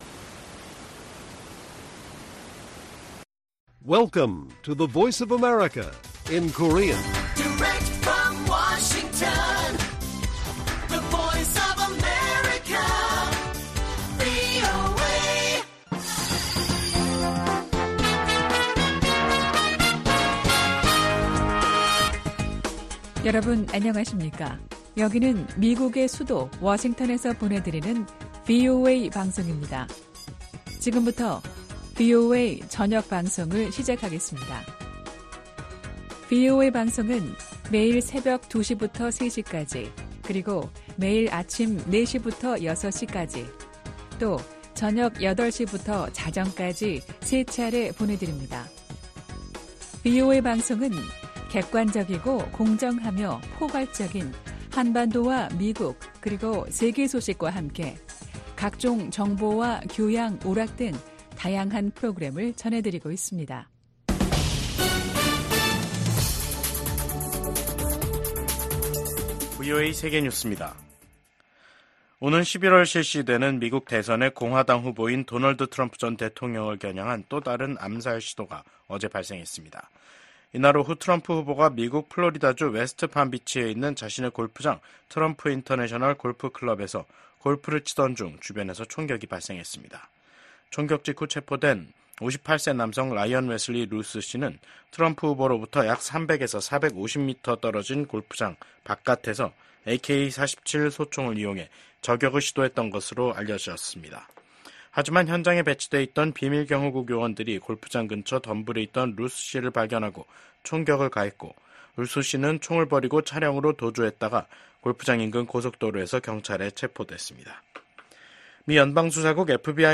VOA 한국어 간판 뉴스 프로그램 '뉴스 투데이', 2024년 9월 16일 1부 방송입니다. 미국 백악관은 북한이 우라늄 농축시설을 공개한 것과 관련해 북한의 핵 야망을 계속 감시하고 있다고 밝혔습니다. 북한은 다음달 초 최고인민회의를 열고 헌법 개정을 논의한다고 밝혔습니다.